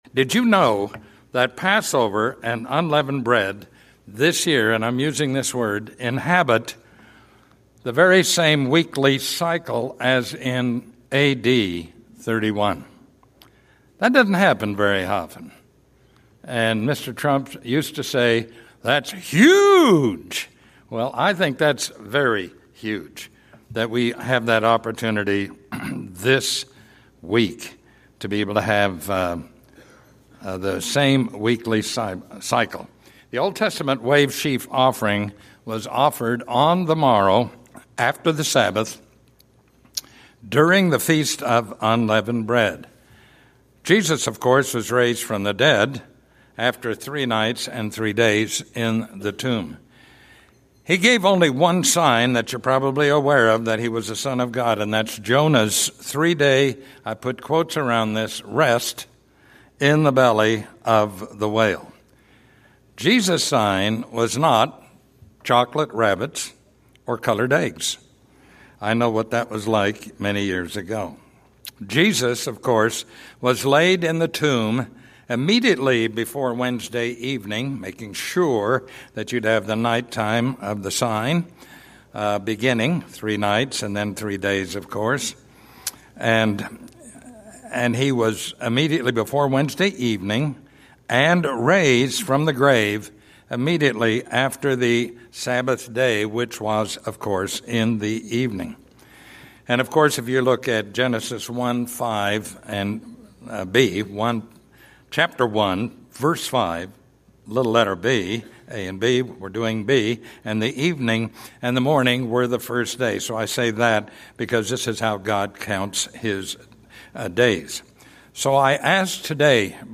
This sermon message explains what God wants us to know.